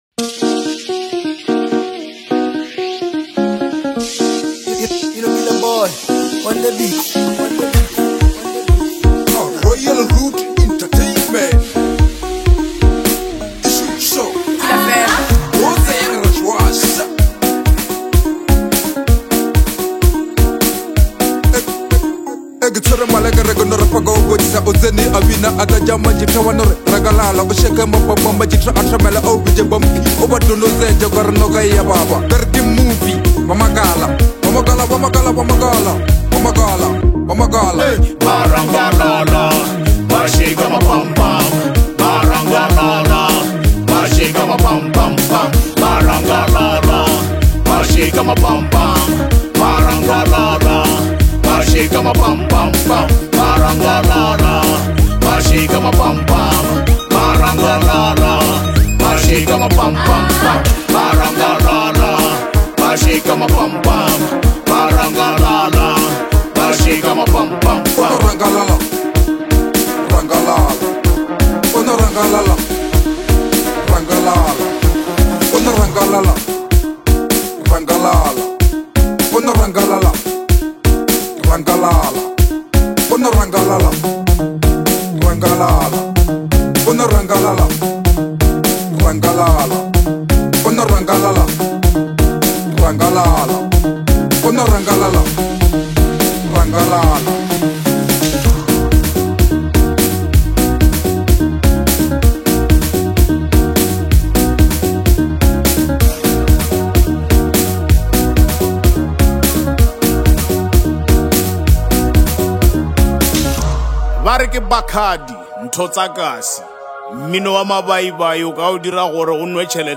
Bolo HouseLekompoMusic